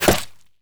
bullet_impact_mud_01.wav